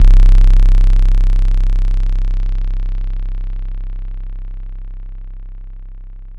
Chill Out Bass.wav